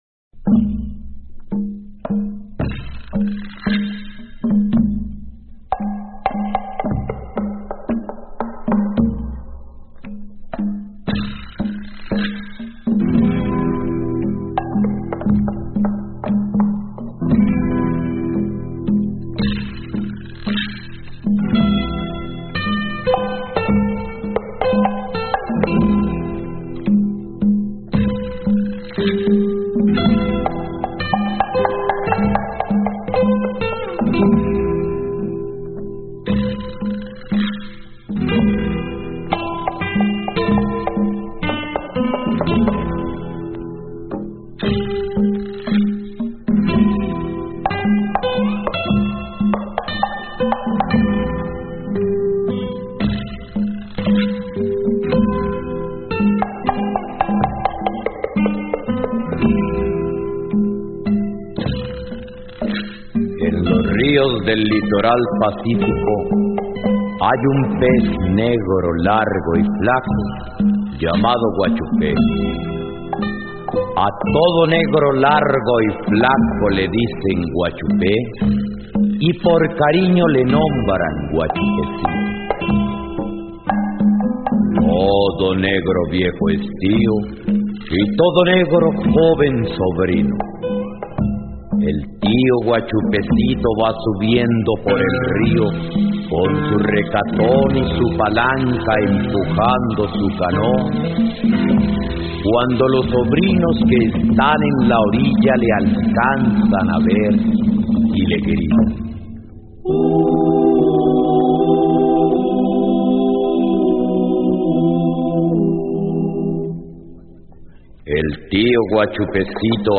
leyenda, Litoral Pacífico Colombiano